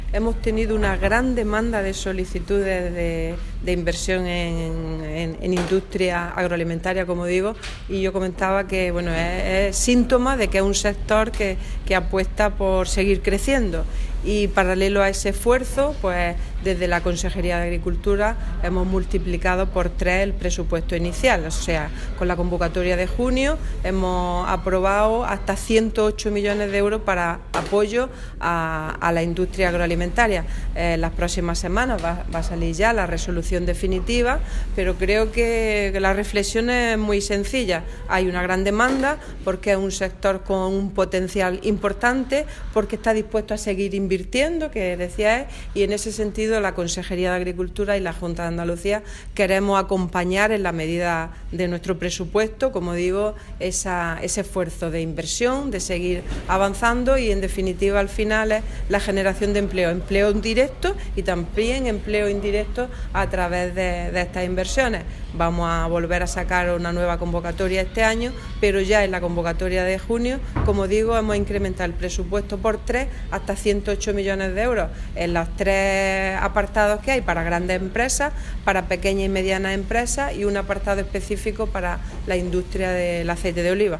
Declaraciones consejera Famadesa